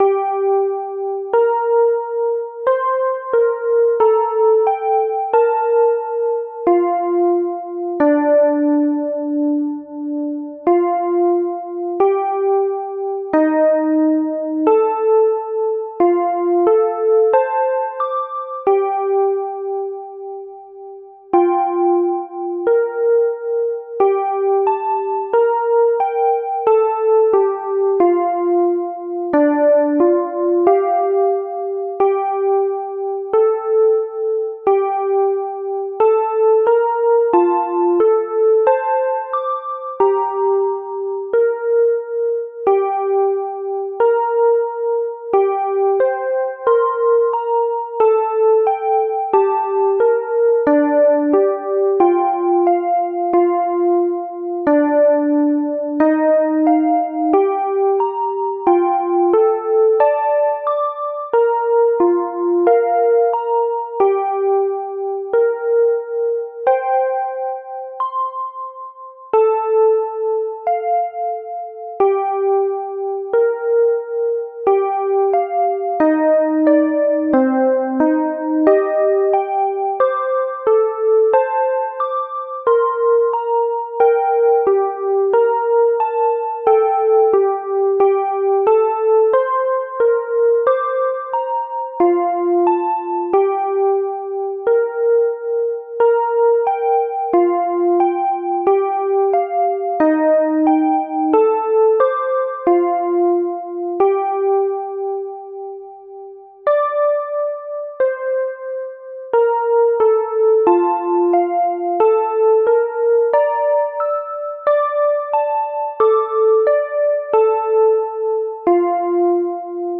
A_New_Restart_corrupt.ogg